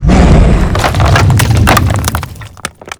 rockstorm.wav